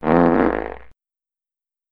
fart.wav